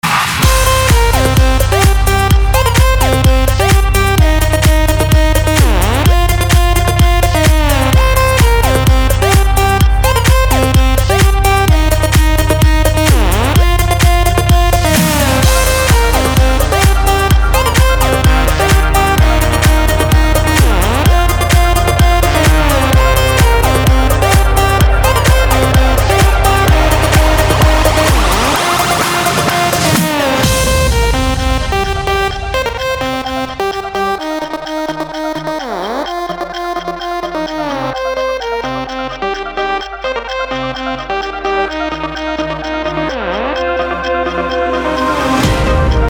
• Качество: 320, Stereo
красивые
без слов
club